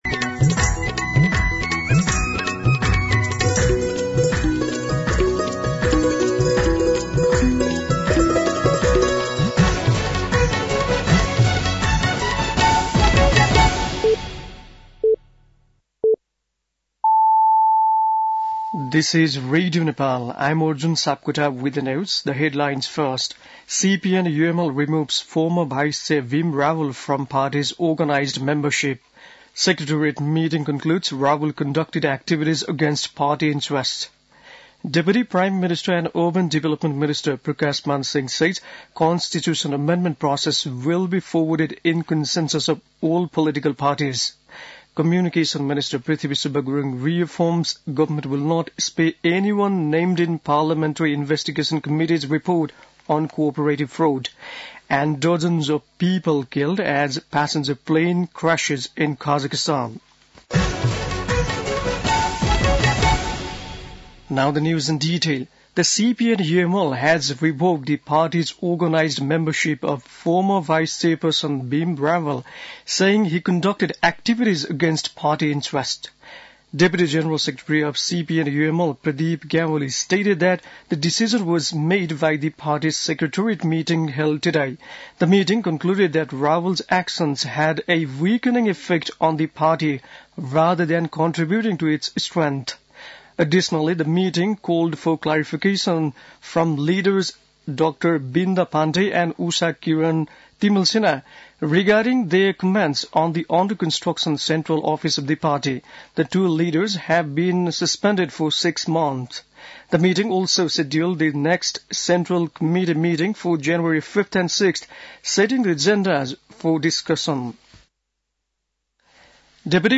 बेलुकी ८ बजेको अङ्ग्रेजी समाचार : ११ पुष , २०८१
8-PM-English-News-9-10.mp3